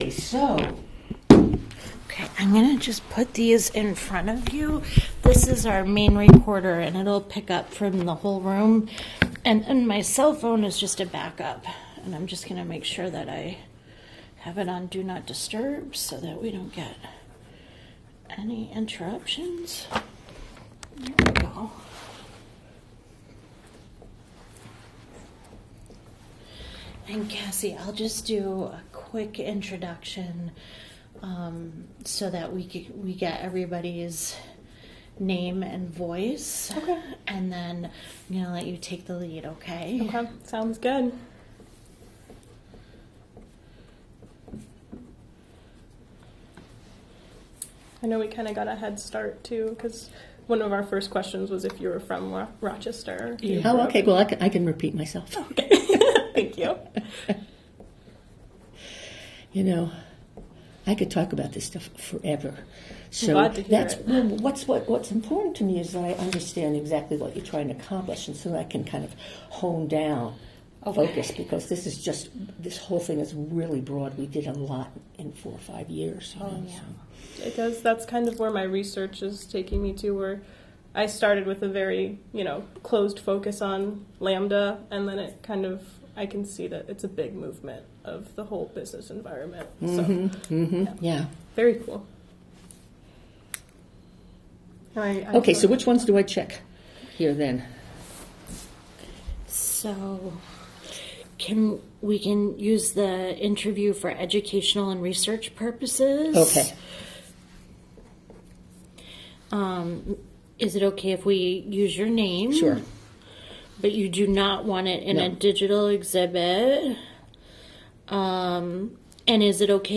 Oral Histories - Lambda Network